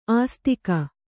♪ āstika